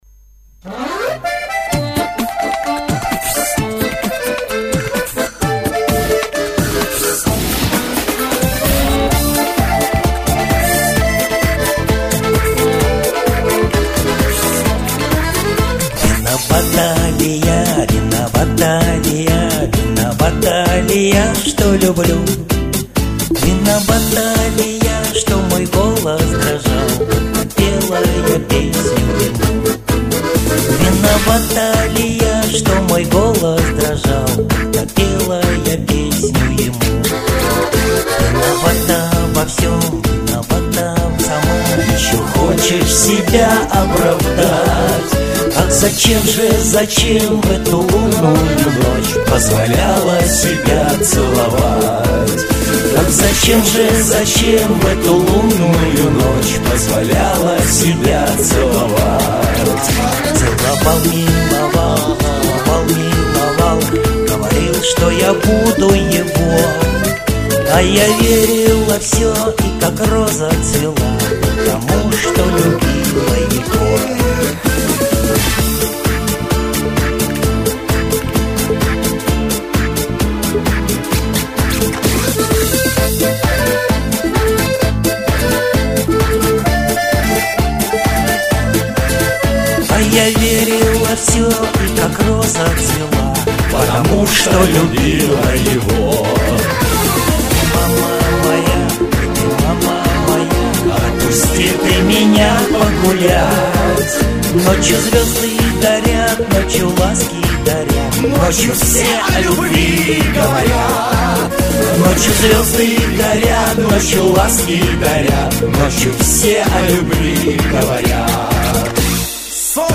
К стати у меня его минусовка !